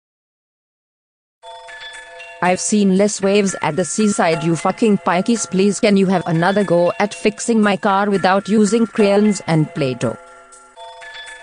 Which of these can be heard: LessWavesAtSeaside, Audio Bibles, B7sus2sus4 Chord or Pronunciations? LessWavesAtSeaside